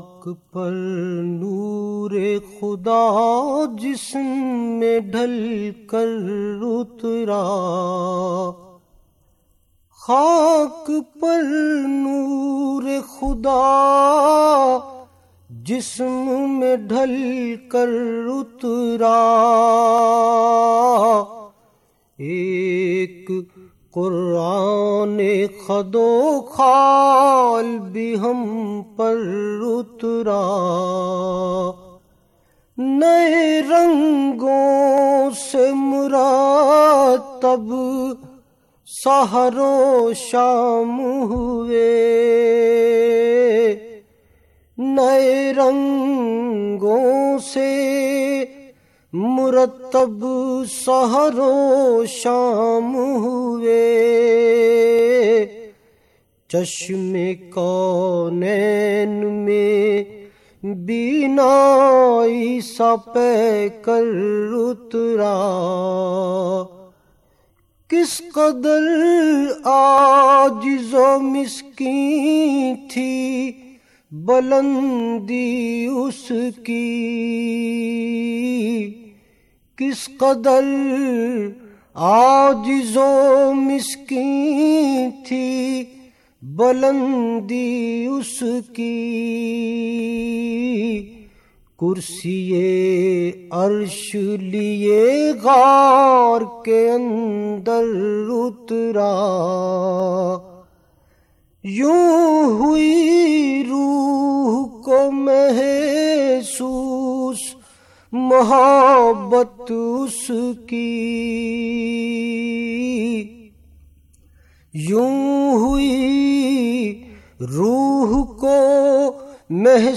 نعت رسول مقبول ص